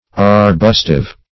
Search Result for " arbustive" : The Collaborative International Dictionary of English v.0.48: Arbustive \Ar*bus"tive\, a. [L. arbustivus, fr. arbustum place where trees are planted.]